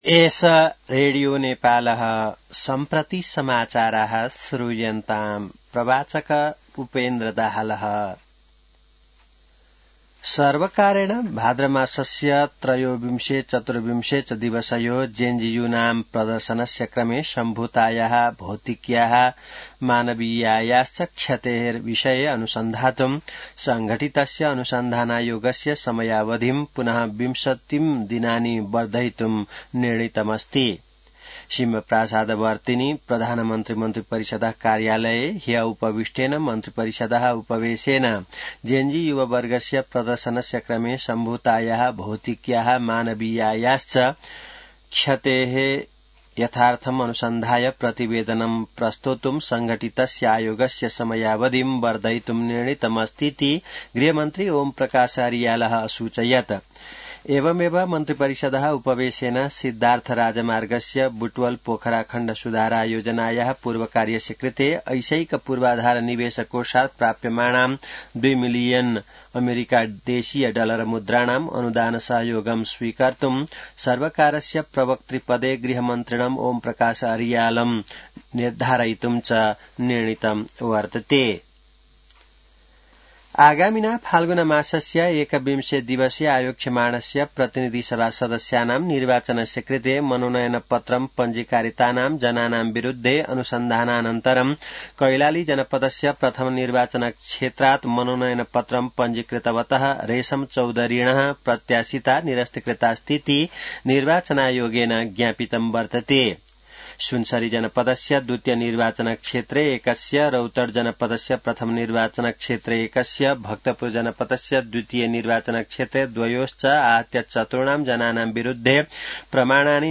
संस्कृत समाचार : ९ माघ , २०८२